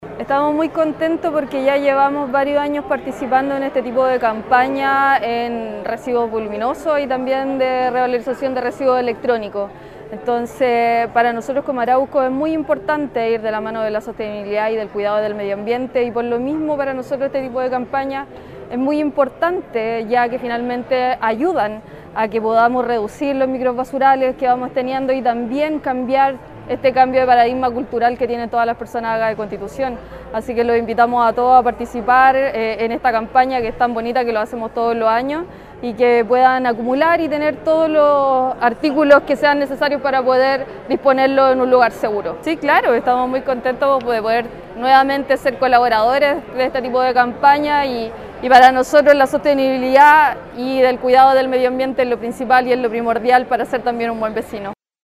Este martes, en el Hall del Teatro Municipal, se llevó a cabo el lanzamiento del Plan de Gestión de Residuos Clasificados, una iniciativa impulsada por el Departamento de Medio Ambiente de la Dirección de Aseo, Ornato y Medio Ambiente.